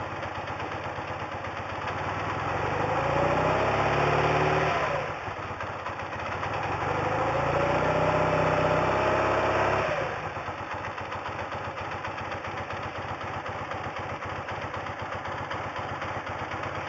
bmw.mp3